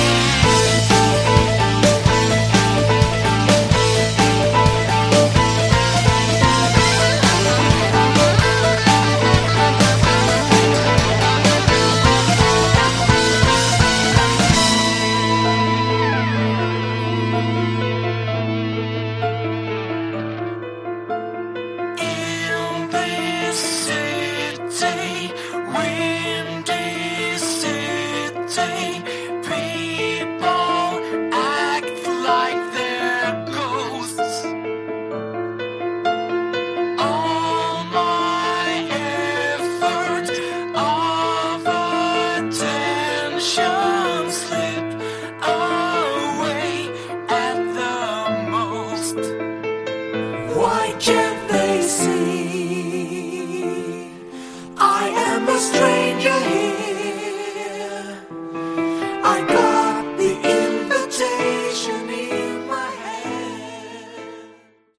Category: Prog/Pomp
Lead & Backing Vocals
Keyboards & Backing Vocals
Electric, Lead & Rhythm Guitars
Bass
Drums
Violin, Viola
Acoustic Guitars
This is great pomp with lots of Kansas influences.